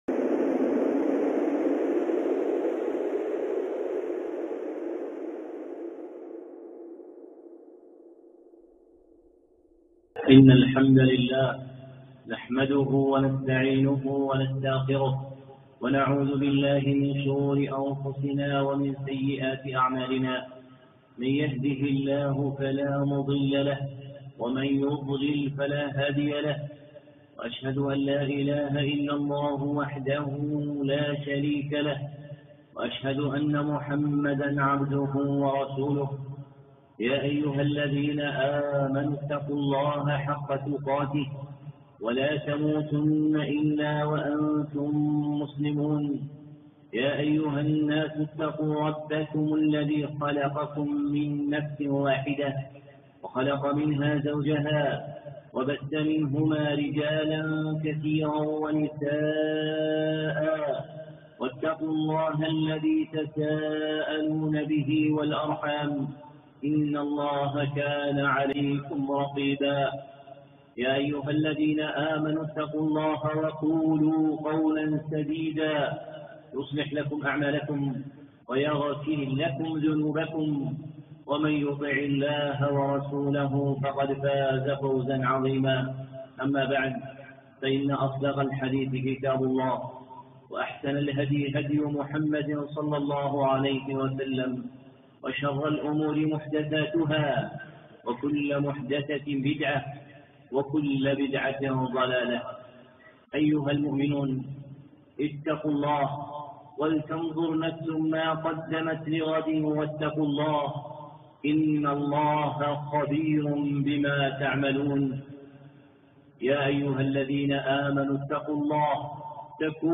خطبة (أبواب الفلاح